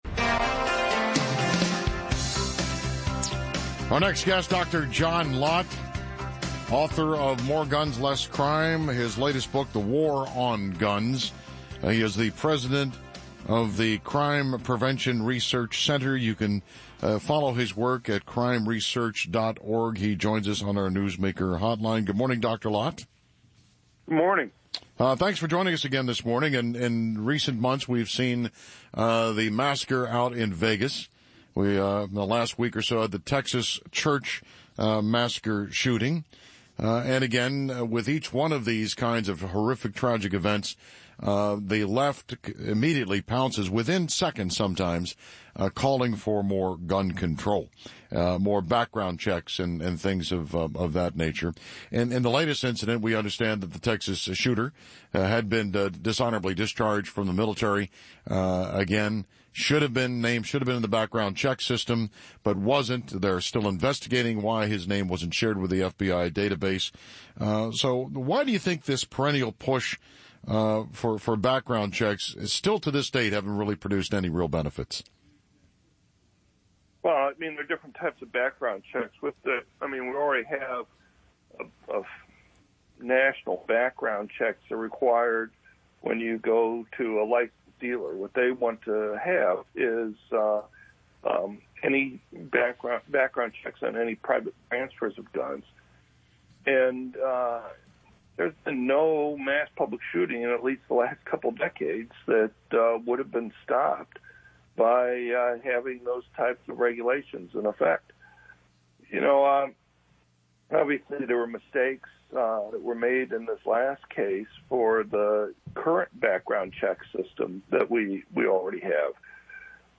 On Maryland's Giant WCBM to discuss "The War on Guns" and the gun control debate - Crime Prevention Research Center